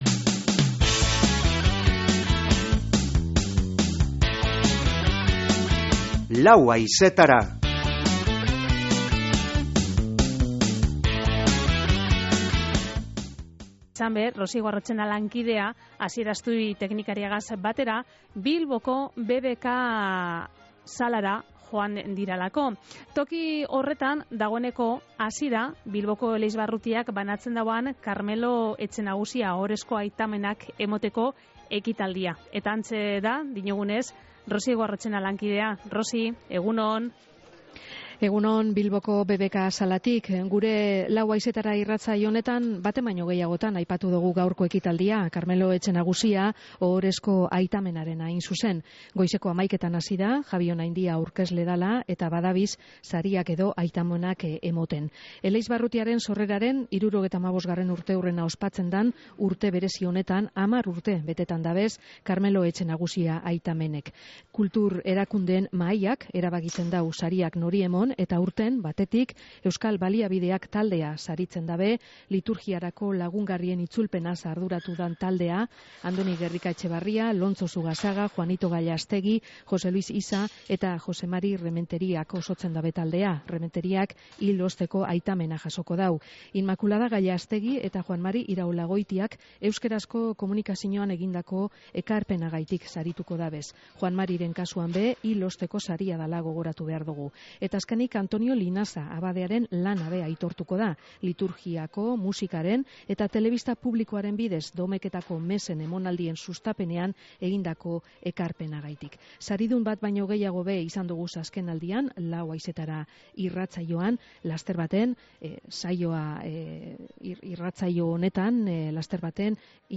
Bilboko BBK Salan izan da ekitaldia